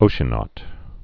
(ōshə-nôt, -nŏt)